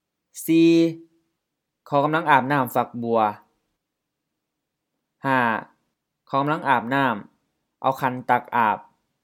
IsaanPronunciationTonesThaiEnglish/Notes
อาบน้ำ a:p-na:m LF-HF อาบน้ำ to take a bath/shower